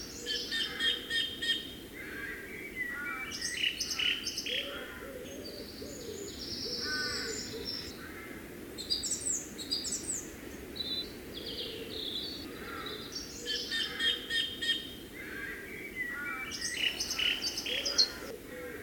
ambiancebird.ogg